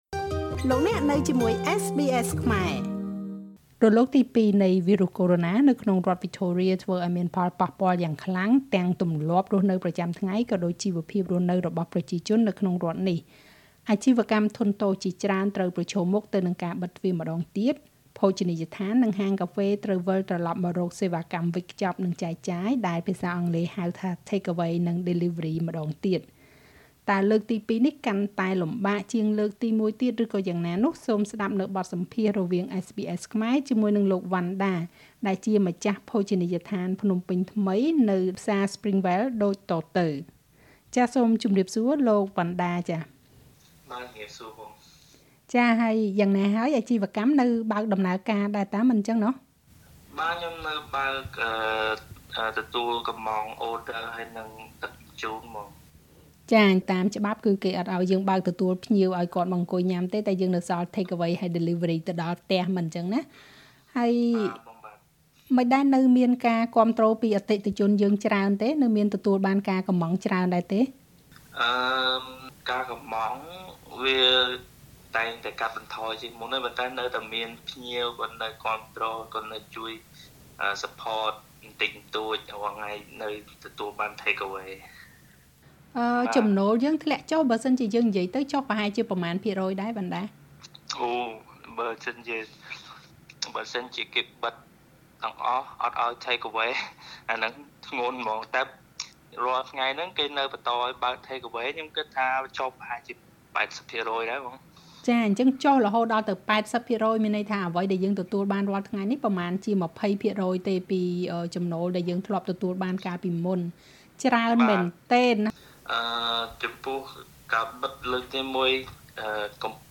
khmer_interview_phnompenh_restaurant.mp3